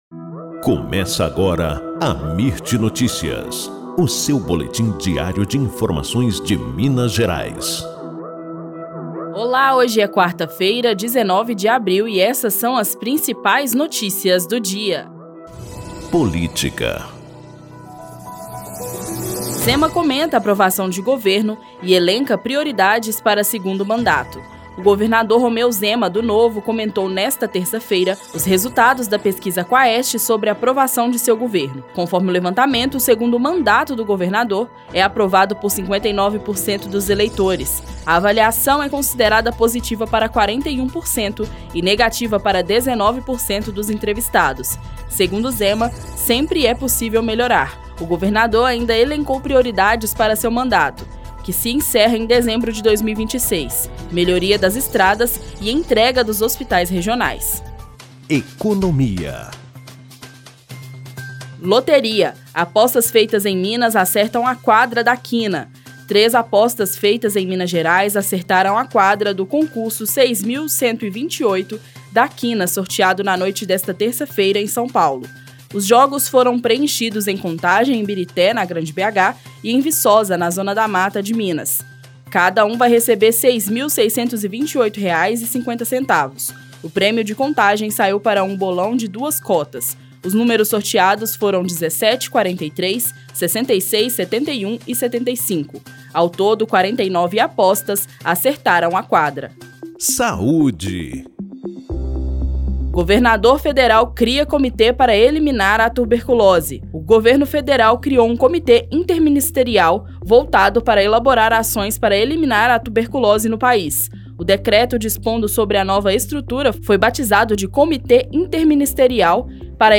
Boletim Amirt Notícias – 19 de abril